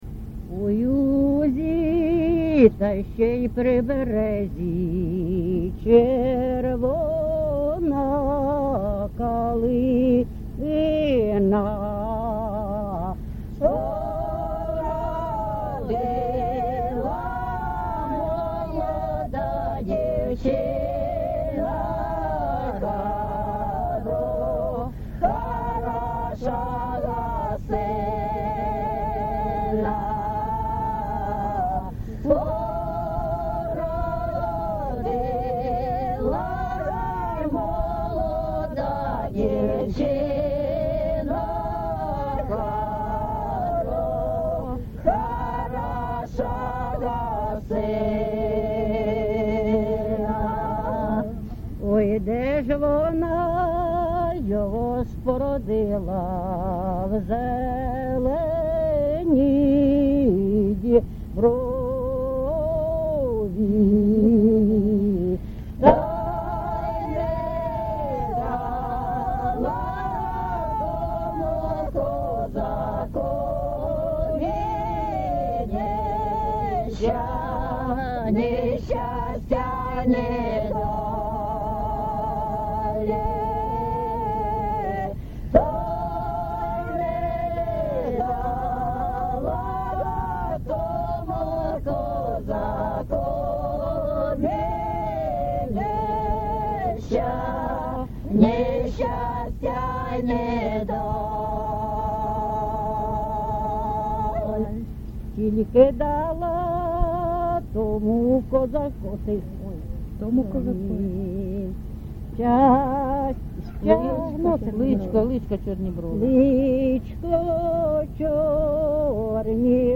ЖанрПісні з особистого та родинного життя
Місце записус. Богородичне, Словʼянський район, Донецька обл., Україна, Слобожанщина